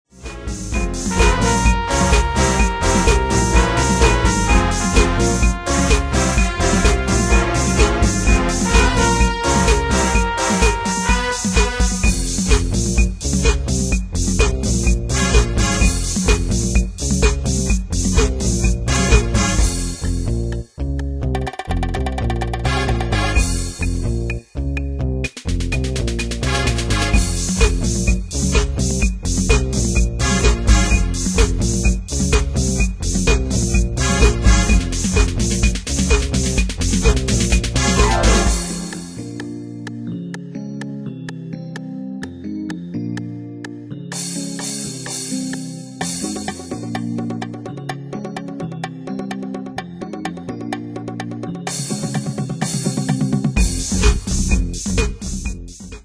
Style: Dance Année/Year: 1994 Tempo: 127 Durée/Time: 4.09
Pro Backing Tracks